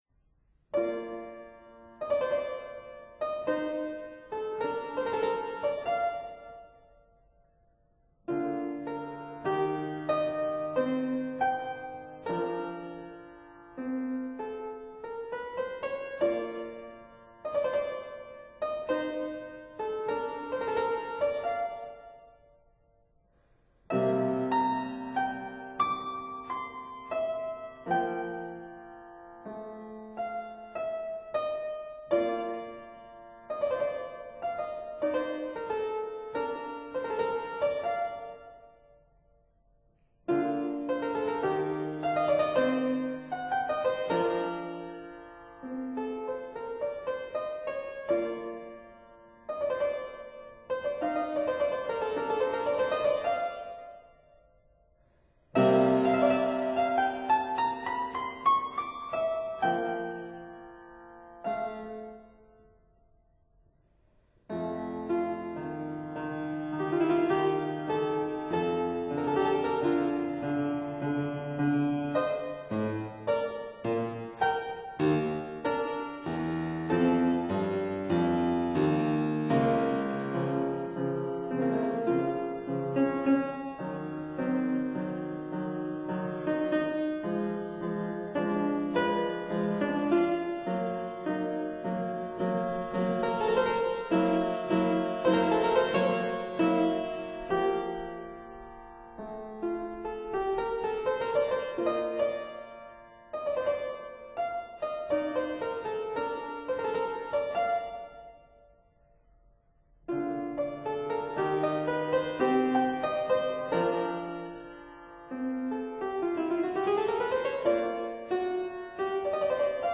fortepiano, organ